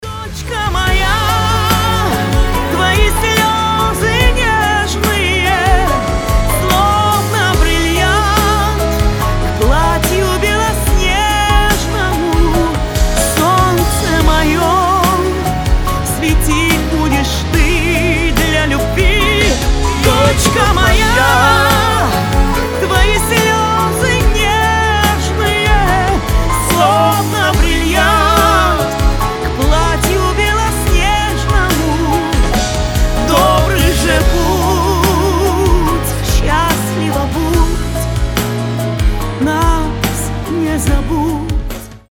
• Качество: 320, Stereo
душевные
дуэт
эстрадные